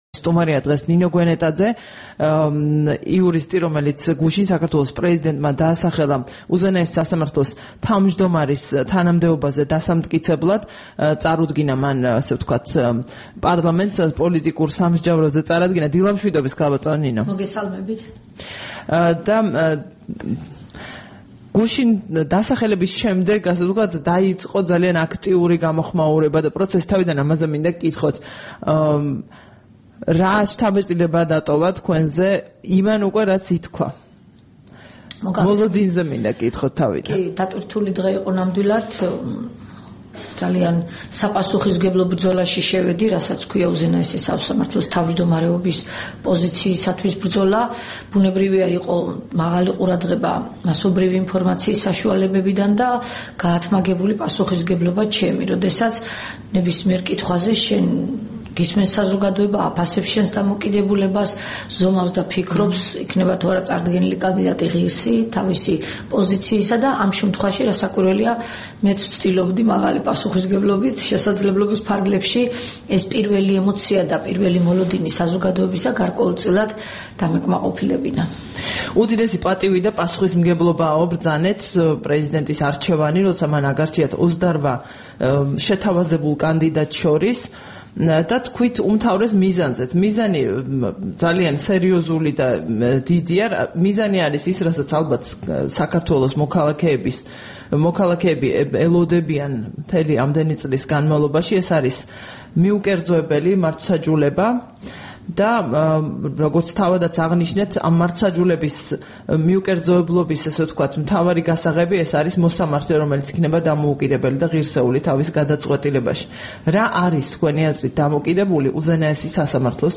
18 თებერვალს რადიო თავისუფლების დილის გადაცემის სტუმარი იყო ნინო გვენეტაძე, პრეზიდენტის მიერ უზენაესი სასამართლოს თავმჯდომარის თანამდებობაზე დასამტკიცებლად დასახელებული კანდიდატი.
საუბარი ნინო გვენეტაძესთან